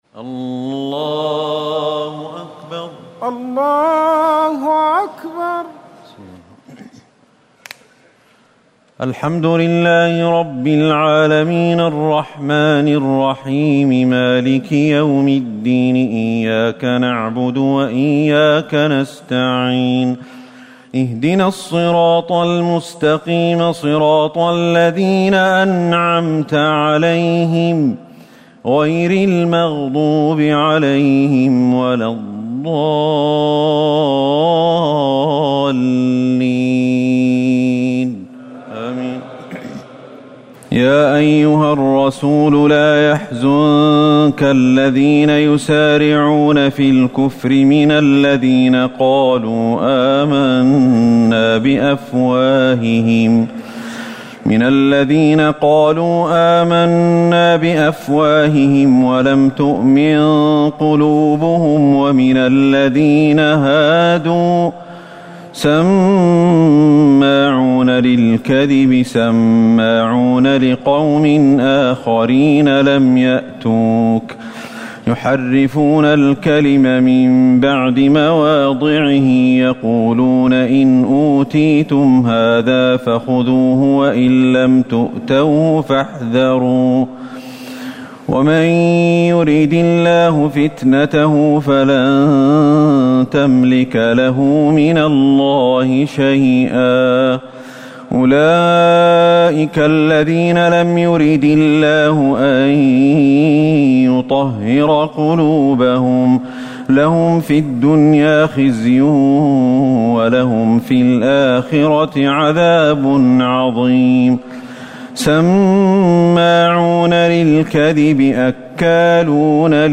تراويح الليلة السادسة رمضان 1439هـ من سورة المائدة (41-108) Taraweeh 6 st night Ramadan 1439H from Surah AlMa'idah > تراويح الحرم النبوي عام 1439 🕌 > التراويح - تلاوات الحرمين